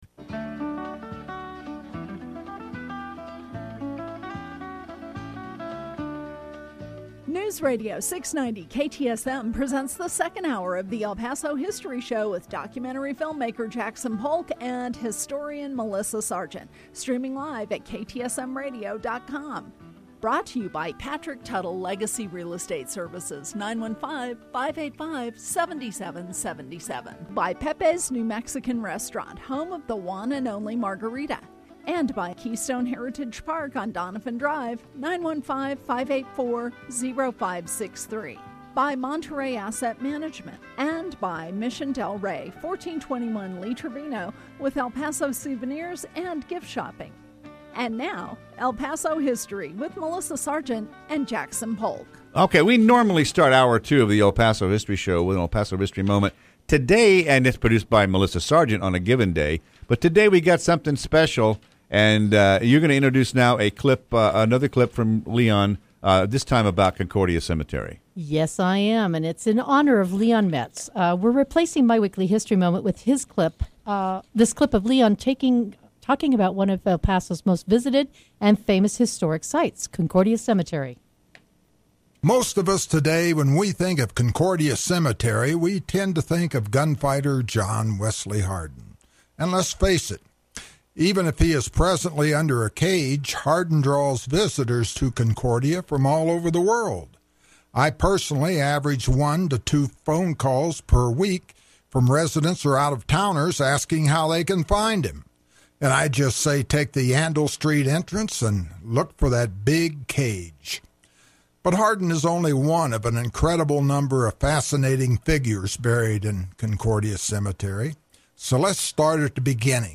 And we played clips of the man himself from the radio show that he hosted for years, and the forerunner to today’s program.